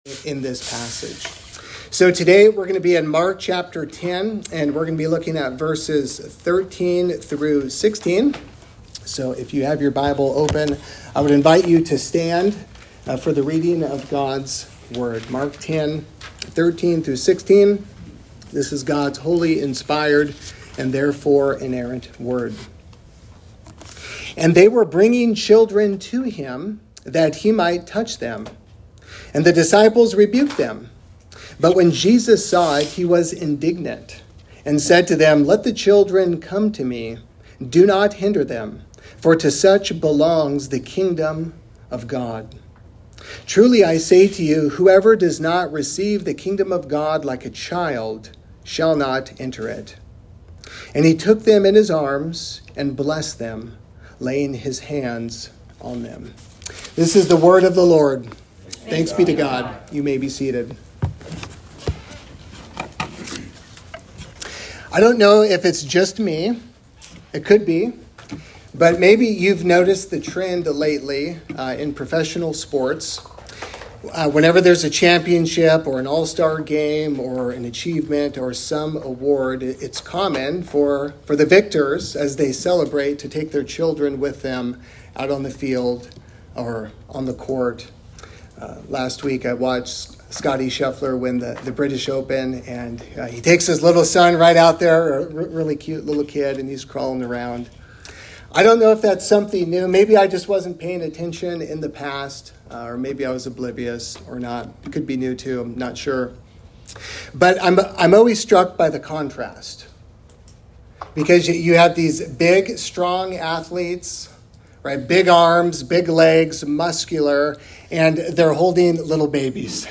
3Rivers Presbyterian Church - Sermons